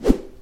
Звуки переходов
Взмах и переход 2